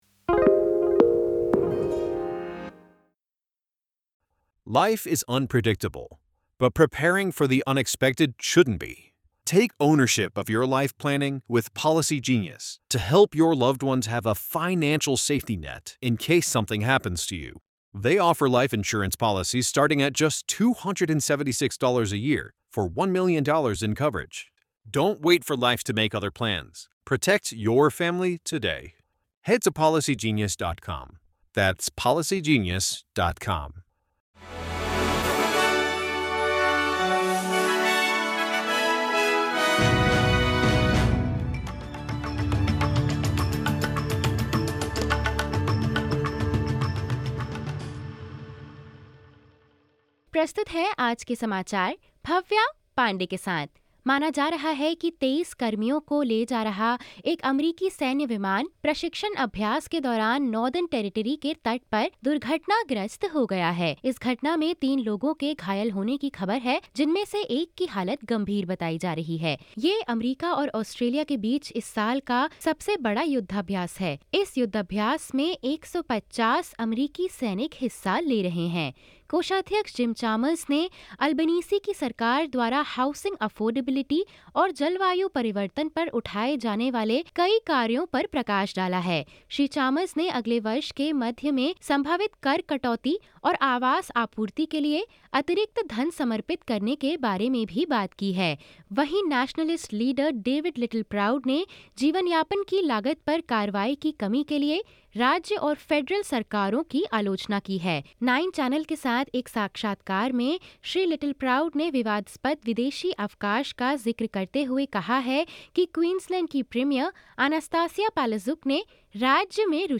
SBS Hindi Newsflash 27 August 2023: Three members of U-S military injured after helicopter crashes in Darwin